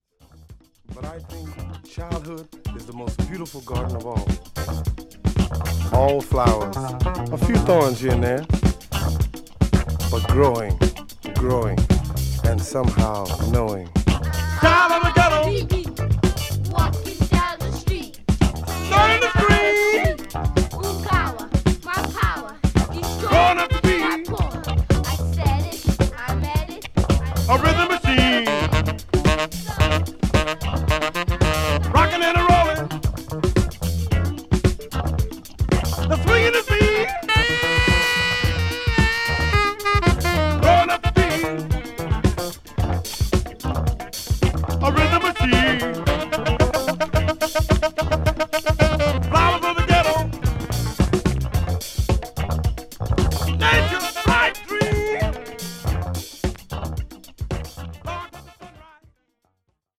クロスオーバー/フュージョン色の濃い内容です